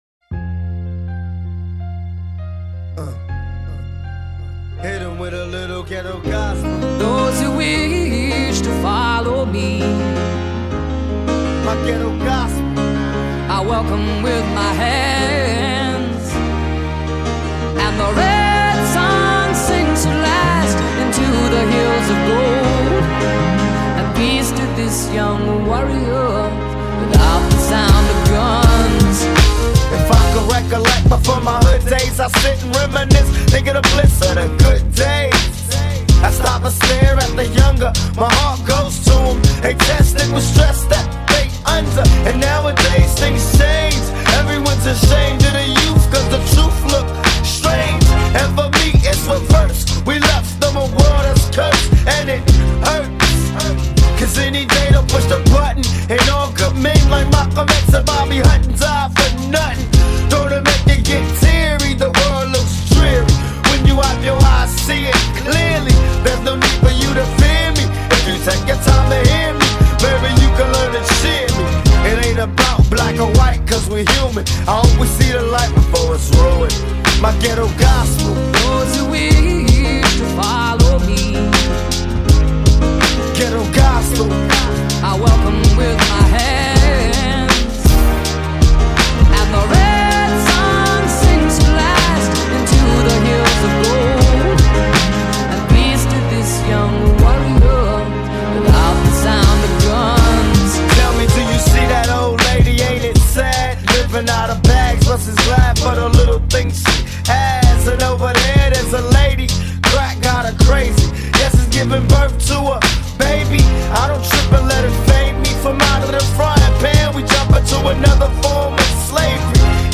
Жанр: foreignrap